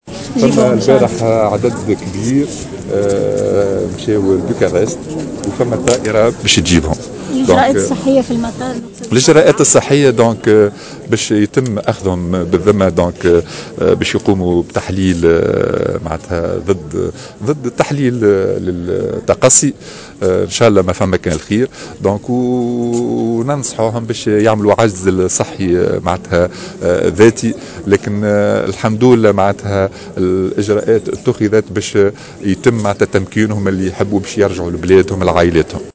من جهته قال وزير الصحة، علي مرابط، في تصريح لمراسل "الجوهرة أف أم"، اليوم الجمعة، على هامش افتتاح قسم طب النفس العام بمستشفى الرازي، إنّه سيتم تخصيص طائرة لإجلاء التونسيين العالقين في أوكرانيا من بوخارست، مضيفا أنه سيتم إخضاعهم جميعا لتحاليل تقصي كورونا، حسب تعبيره. وزير الصحة علي مرابط تحميل المشاركة علي